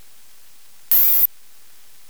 Aufgezeichte habe ich das Baseband (0,25 MSPS) eines Becker-Funk Sonnensensors.